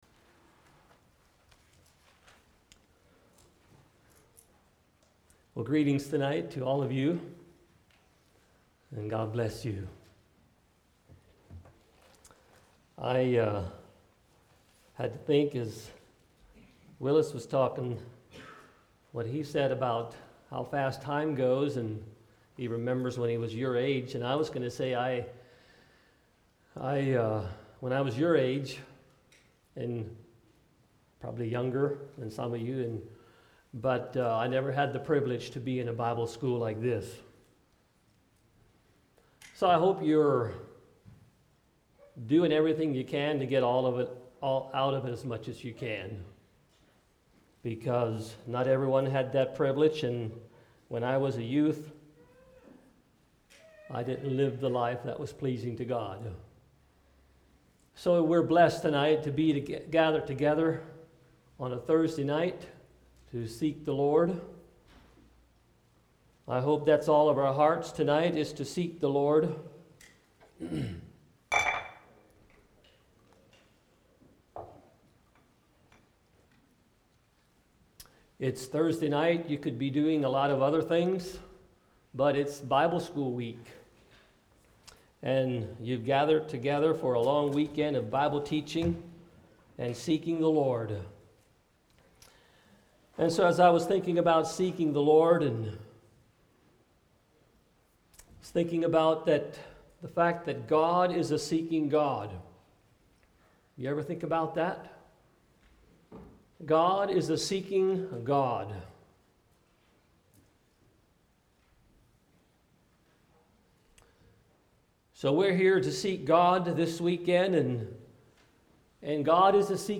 Youth Bible School 2021 God is looking for people who are pure, without spot or wrinkle. Have you surrendered everything to him?